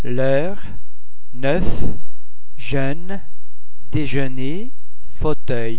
eu oeu oe ue vowel-base simimlar to ir in ( sir)
The French [ ir ] [ er ] sound is similar to the vowel sound in English words like sir or herd. To be produced with almost no lip-rounding.